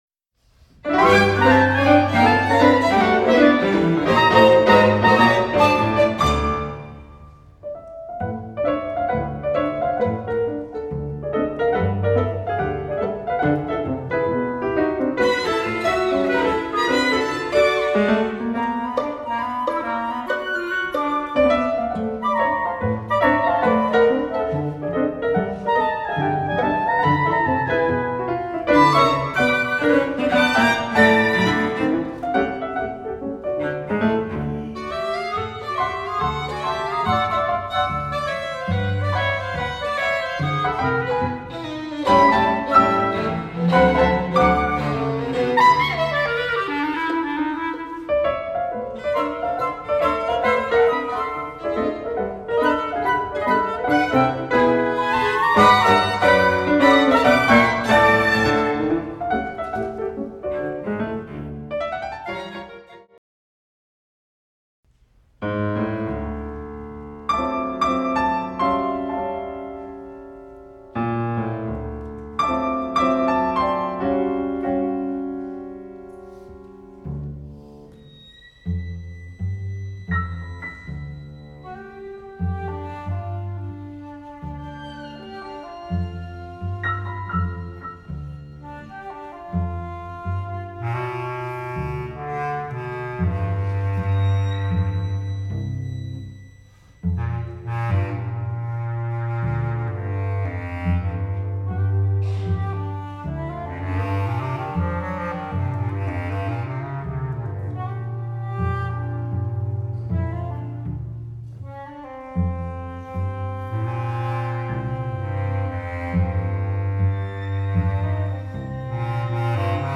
Flute(dPicc), Clarinet(dBCl), Violin, Cello, Piano